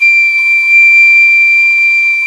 PICOLO1-D5.wav